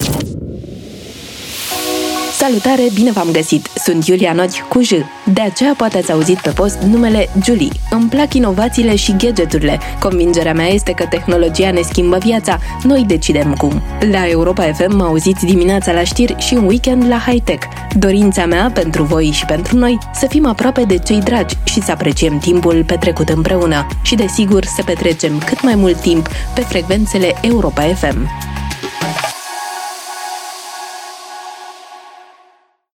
Câteva dintre mesajele colegelor noastre: